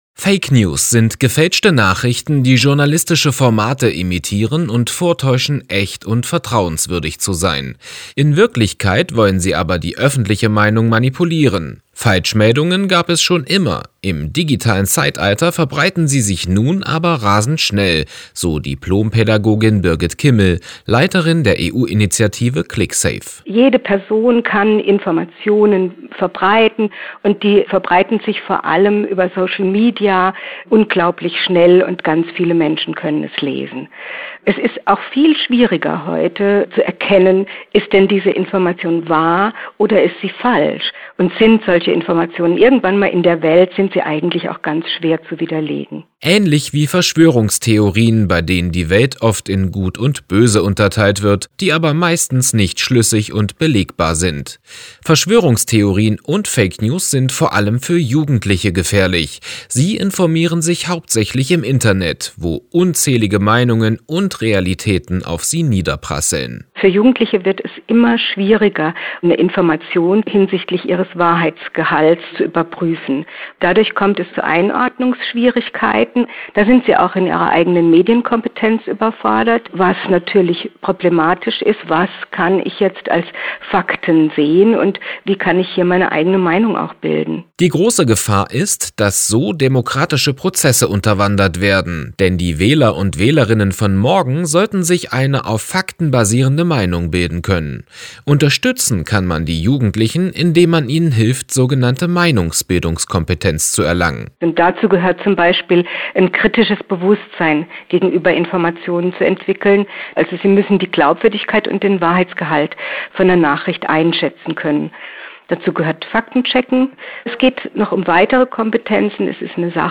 Audio-Interview